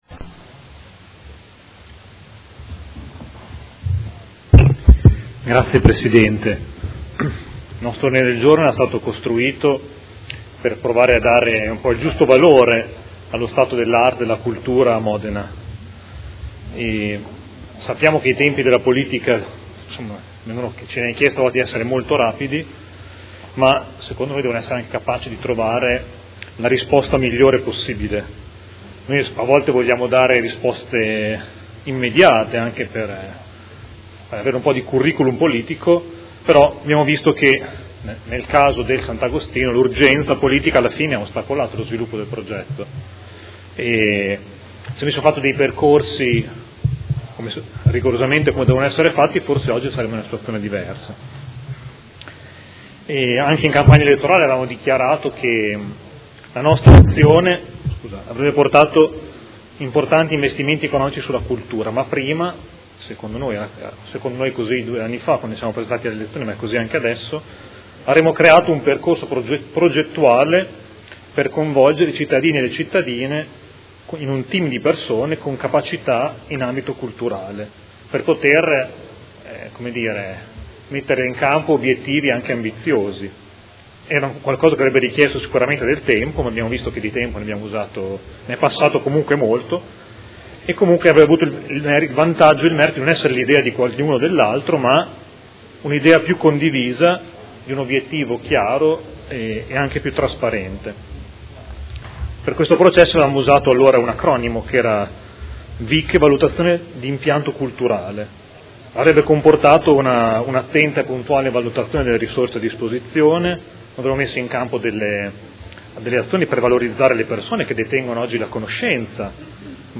Seduta del 12/05/2016. Dibattito su Ordine del Giorno presentato dai Consiglieri Trande, Pacchioni, Poggi, Di Padova e Bortolamasi (P.D.) avente per oggetto: Prime azioni istruttive del Consiglio Comunale a sostegno e in accompagnamento al progetto del Polo Culturale Sant’Agostino e Ordine del Giorno presentato dal Gruppo Movimento Cinque Stelle avente per oggetto: Piano per la valorizzazione dei beni culturali modenesi